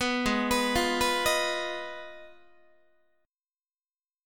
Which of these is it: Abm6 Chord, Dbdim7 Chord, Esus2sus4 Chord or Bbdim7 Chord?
Abm6 Chord